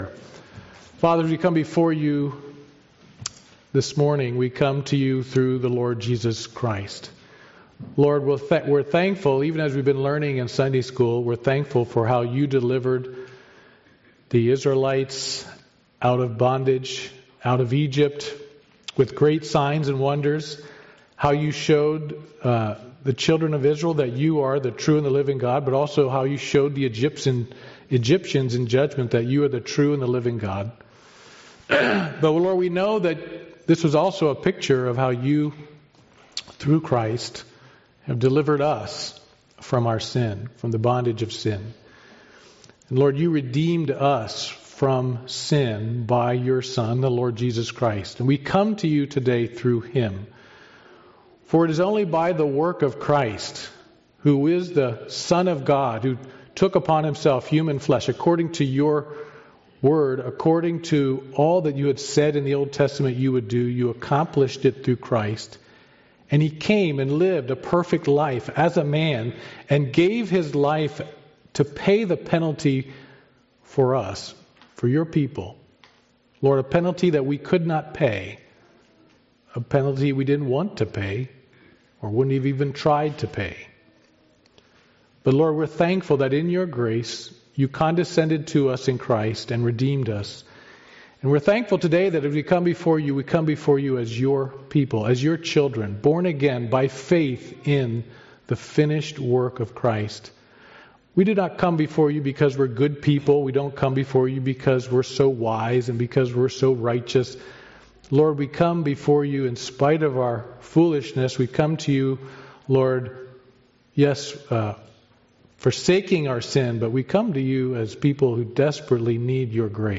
Passage: Job 21 Service Type: Sunday Morning Worship « The Lords Message Messengers and Method 37 Gods View of Davids Sin